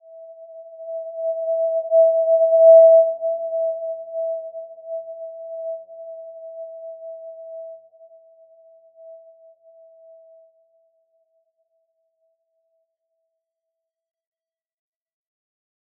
Simple-Glow-E5-mf.wav